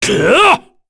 Dakaris-Vox_Attack6_kr.wav